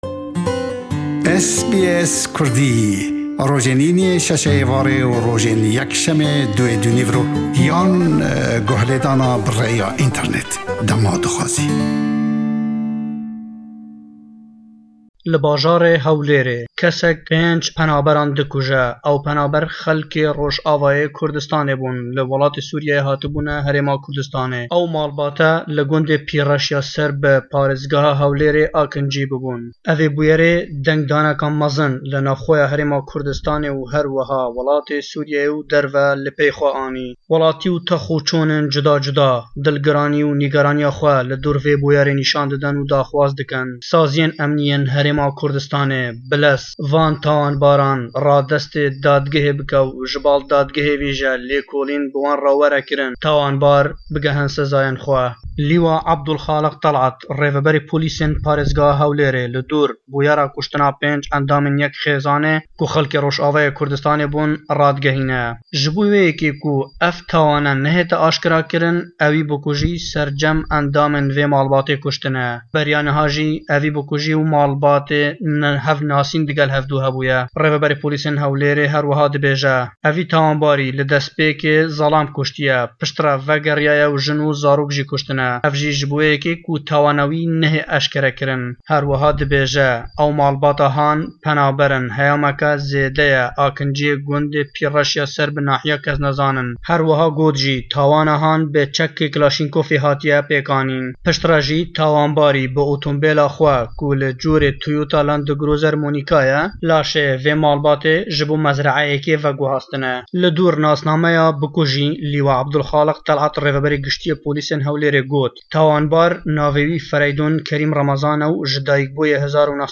raport dekat ser wellamy fermanberekan.